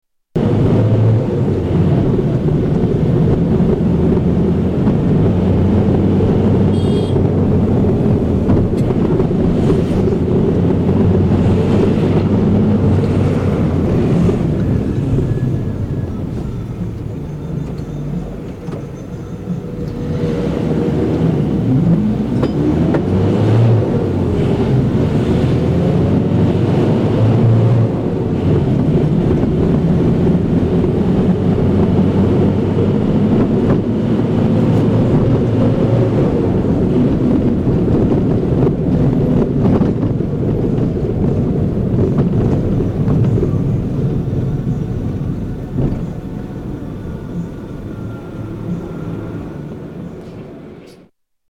Public transportation at Bogota